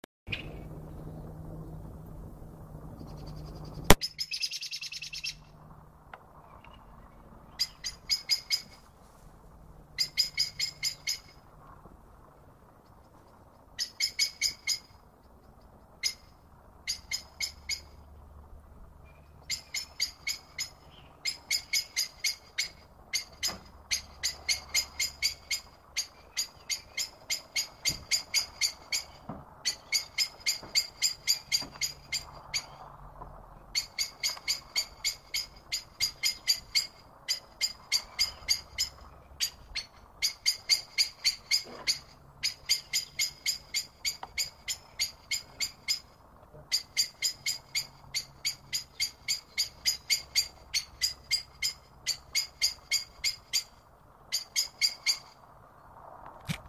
Loud Bird Near Home. Who thinks this is bird version of noisy child in supermarket?